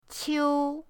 qiu1.mp3